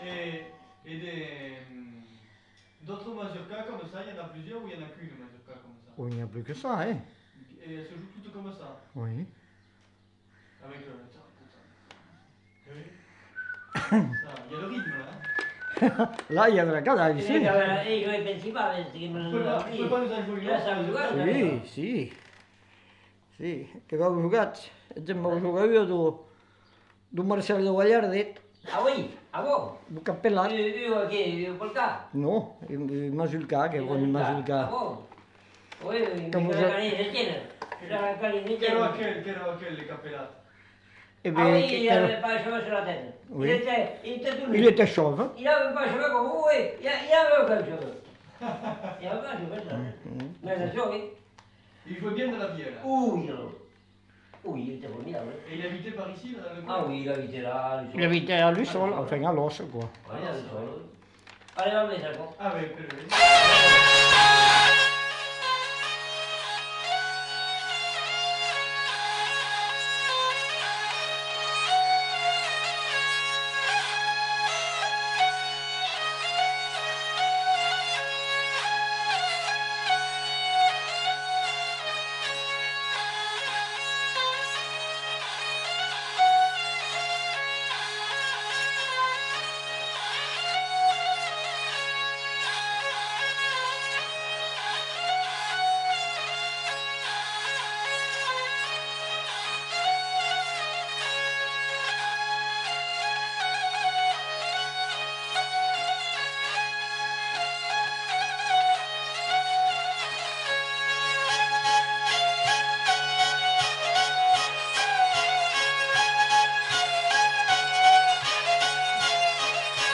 Lieu : Vielle-Soubiran
Genre : morceau instrumental
Instrument de musique : vielle à roue
Danse : mazurka